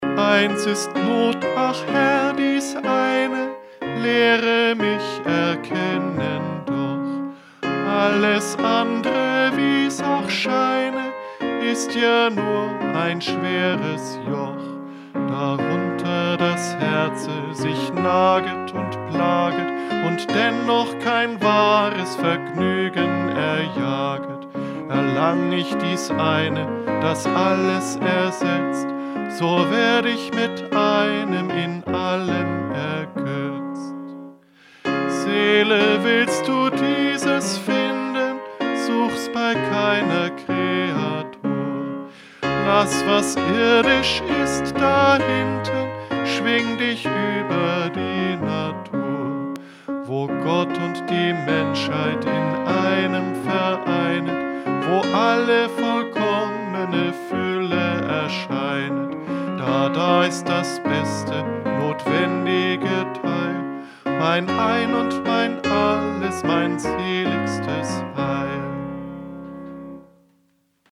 Eingesungen: Liedvortrag,